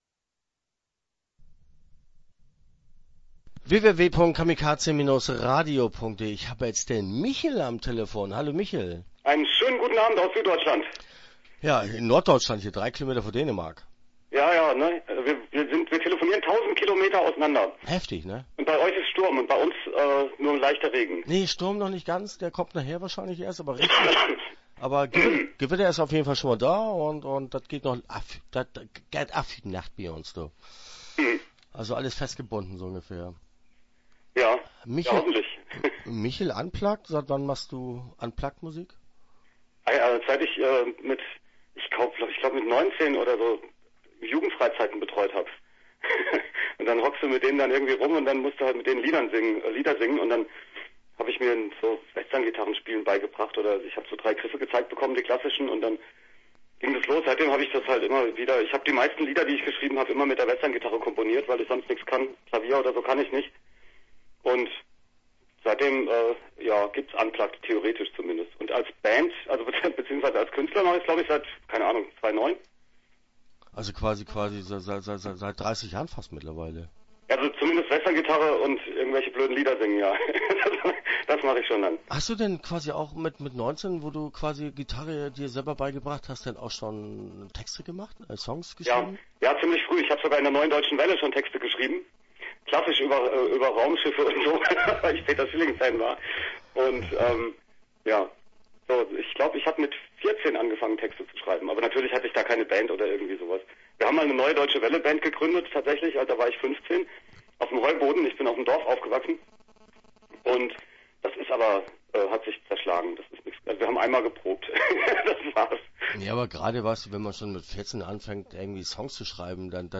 Interview Teil 1